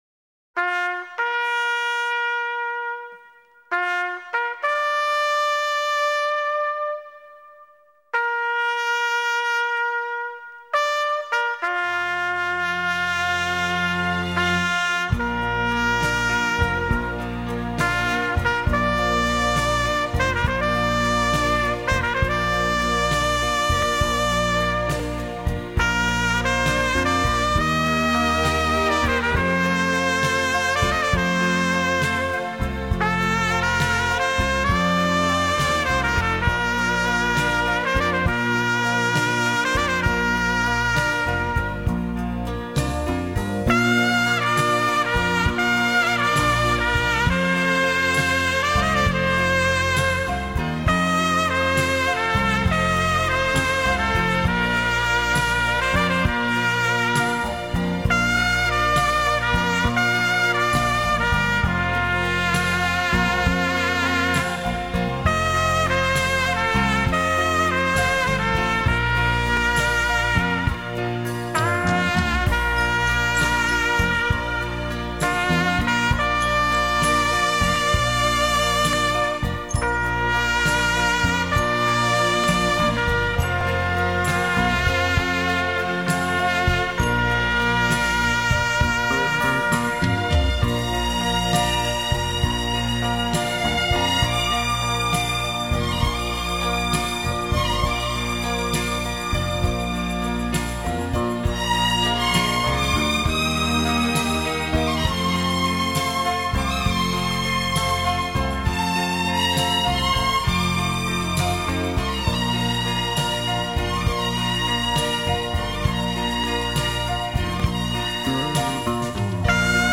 [纯音乐]
小号音色明朗响亮，非常锐利。
好美的旋律呀谢谢分享！